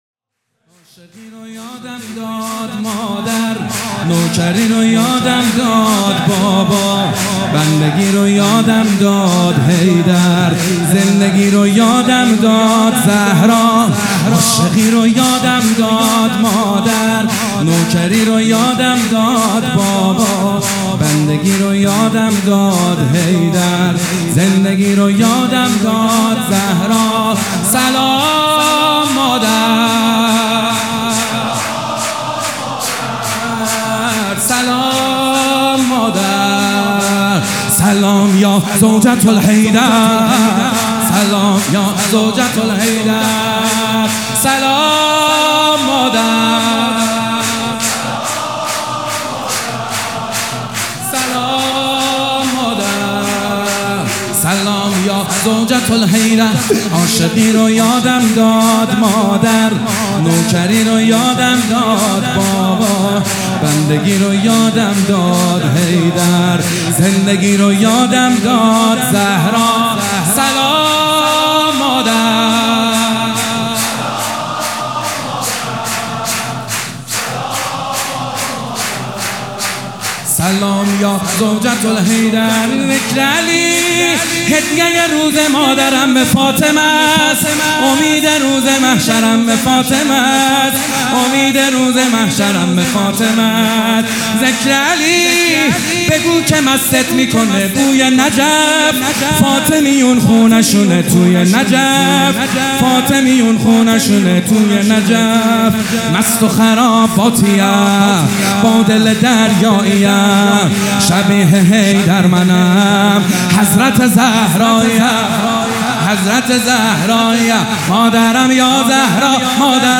حسینیه ریحانة‌الحسین (س)
شور
کربلایی محمدحسین حدادیان